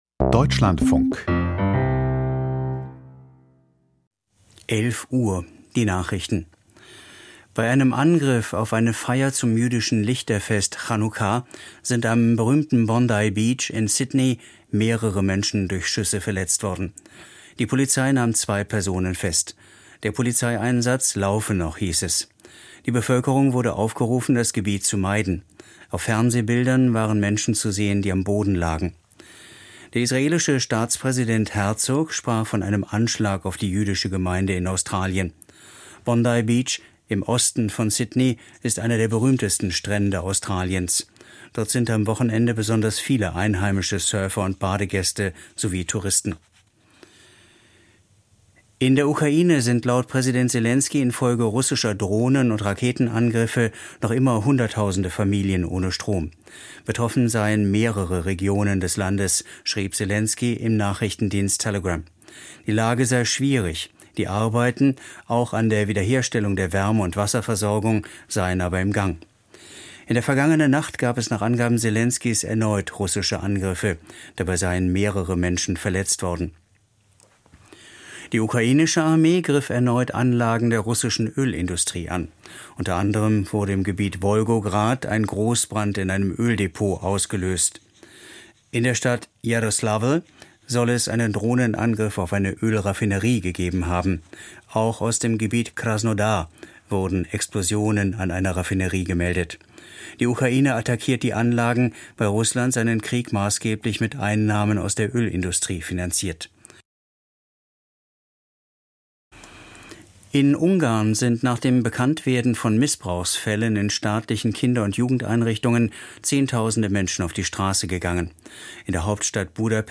Die Nachrichten vom 14.12.2025, 11:00 Uhr
Aus der Deutschlandfunk-Nachrichtenredaktion.